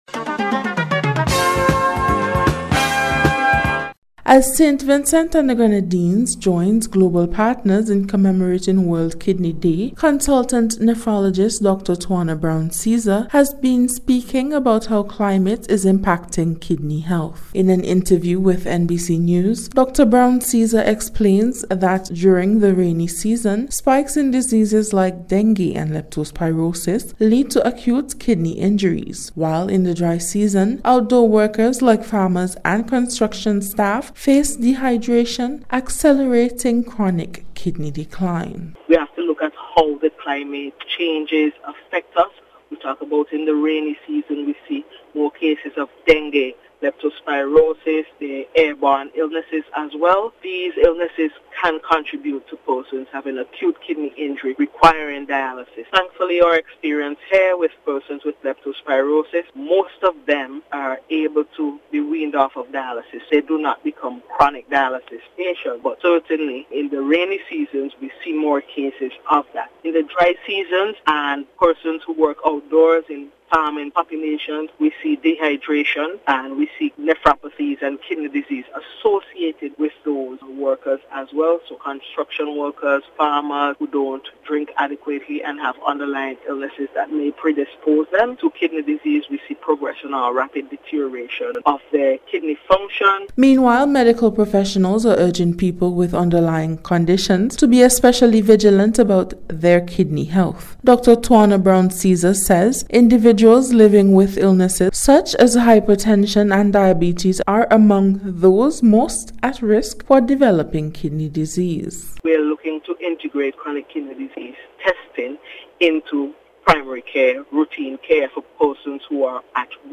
KIDNEY-CLIMATE-CHANGE-REPORT.mp3